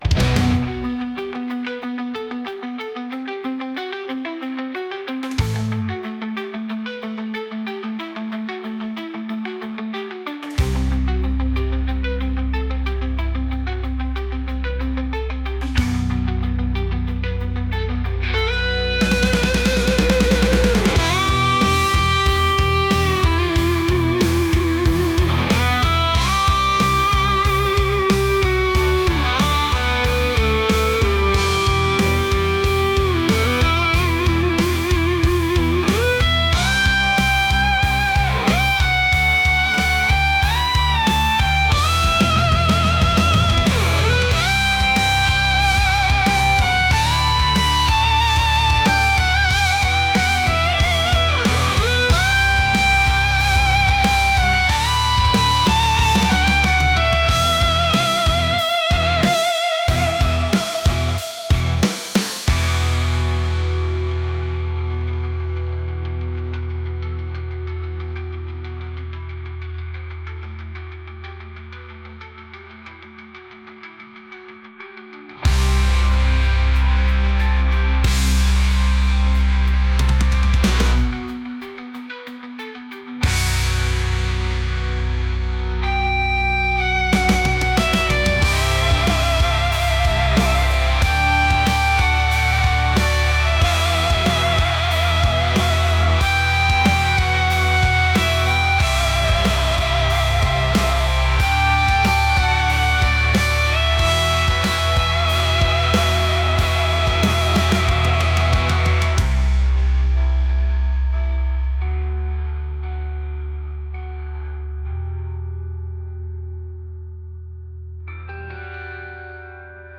heavy | rock